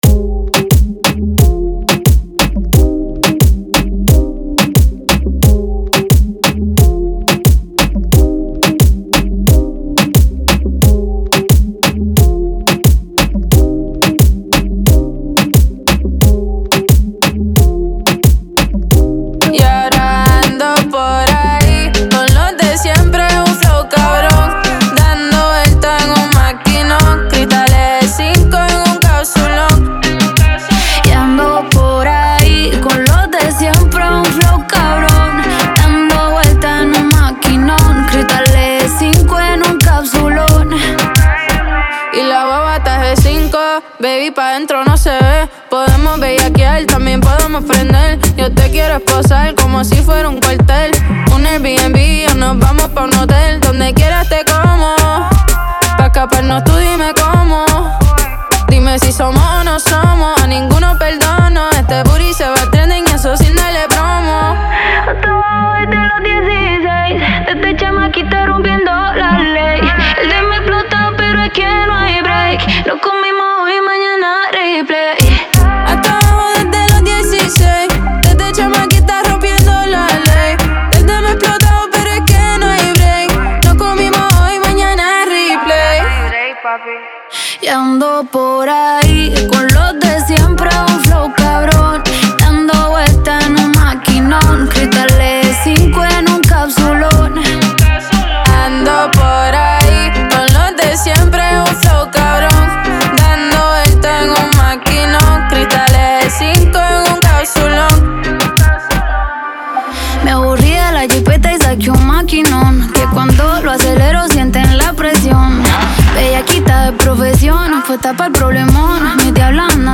Genre: Reggaeton.